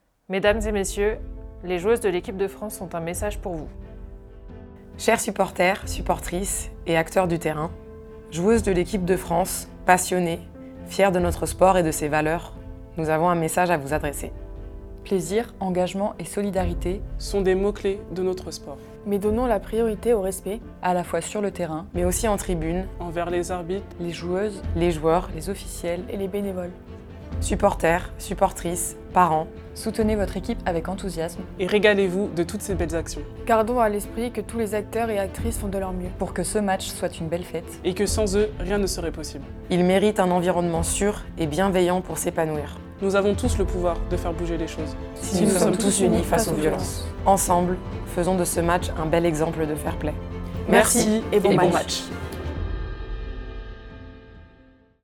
Diffusion de l’audio “TOUS UNIS” avant les matchs
FFHandball_audio_tous_unis-EDFF-avec-musique.wav